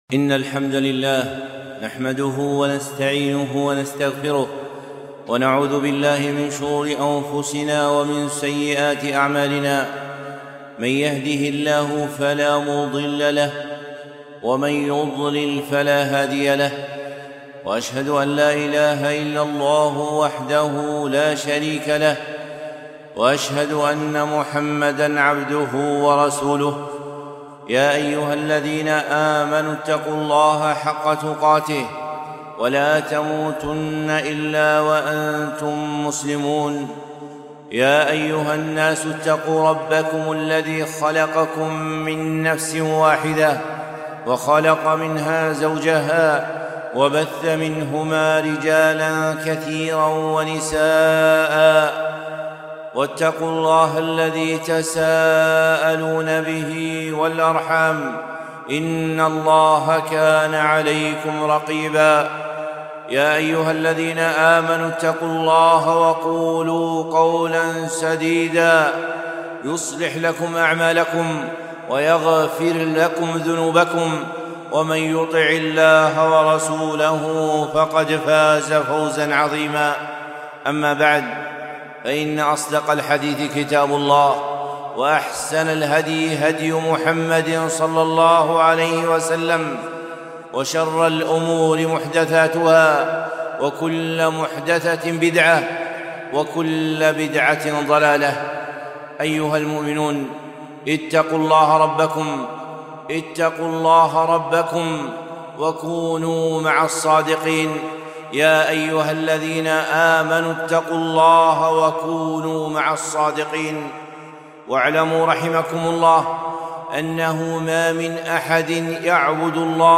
خطبة - هل أنت من المحبوبين؟